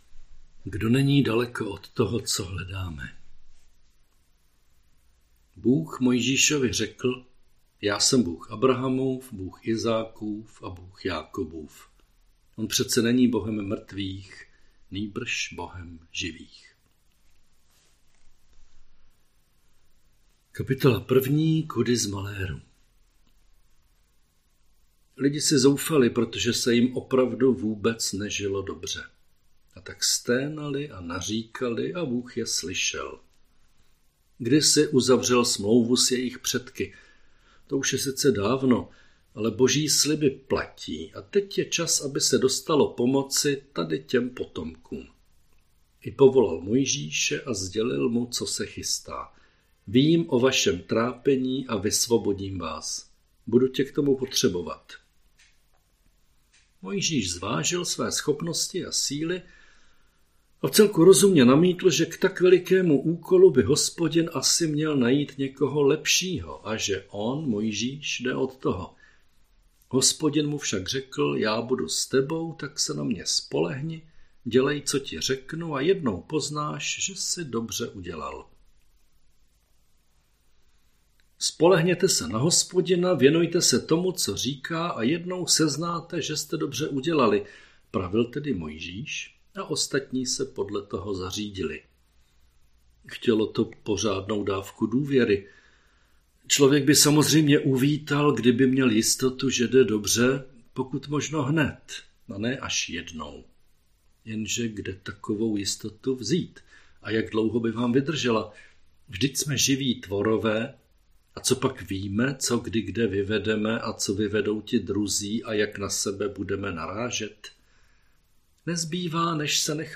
Přehled kázání